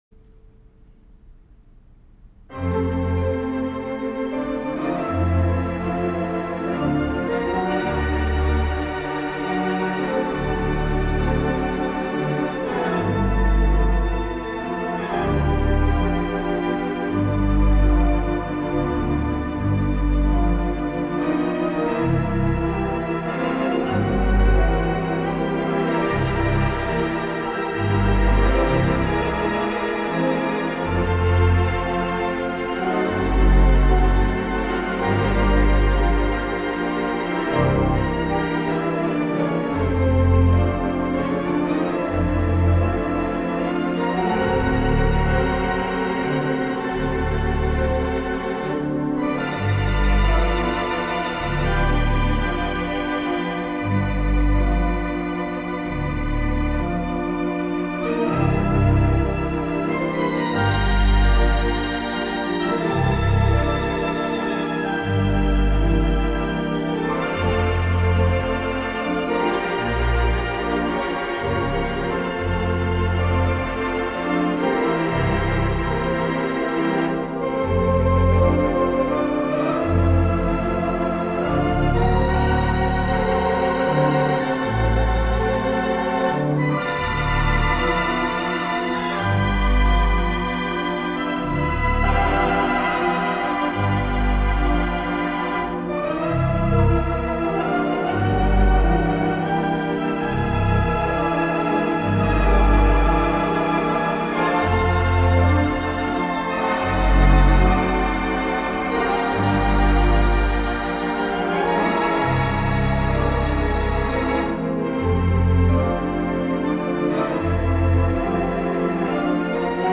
J. Tyson Forker Memorial 4/32 Mighty WurliTzer Theatre Pipe Organ
installed at Grace Baptist Church in Sarasota, Florida.
I am not super happy with the sound.
(In Waltz time.)